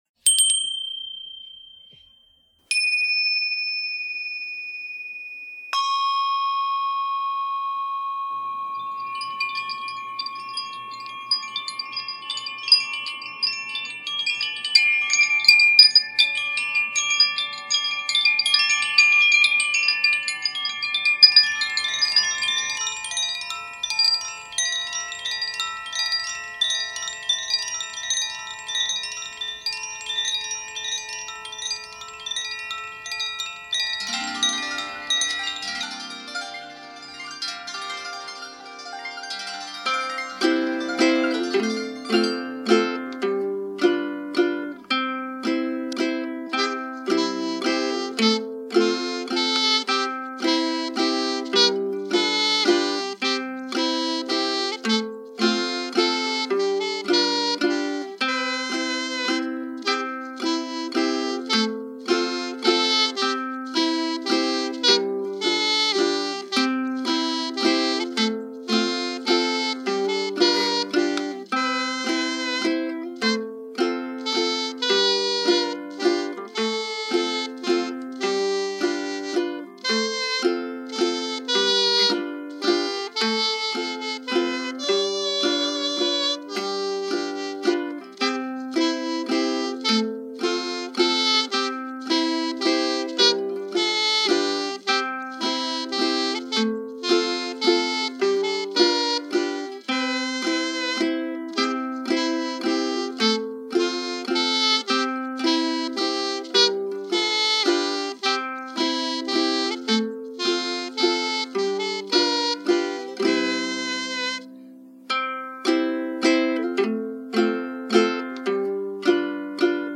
Here is a waltz I wrote for this Sabbat. I hope this joyful melody makes you smile!
Triple Moon Bell
Tibetan Tingsha
Tibetan Bell
Zaphir Crystallide Chime
Zaphir Blue Moon Chime
Moon Harp
Ukulele
Nuvo Dood (recorder/clarinet hybrid)
Nuvo jFlute
219ba-may_day_waltz.mp3